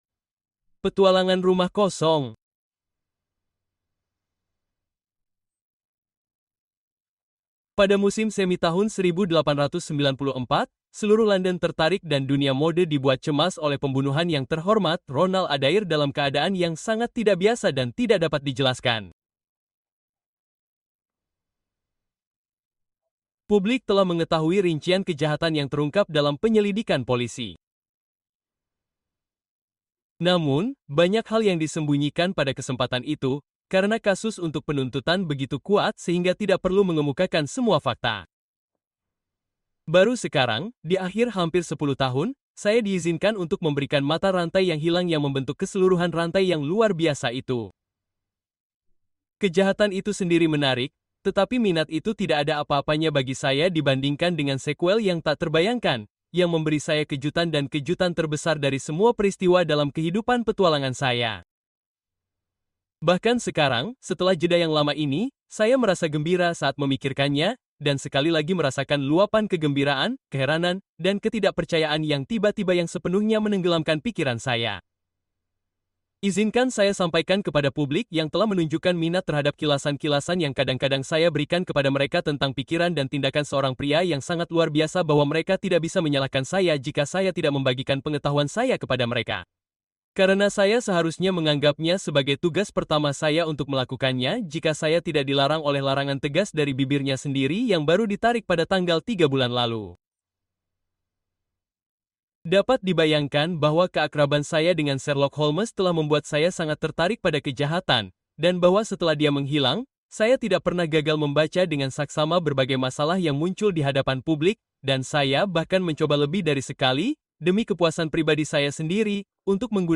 The Creeping Man: Sherlock Holmes’ Most Puzzling Case (Audiobook)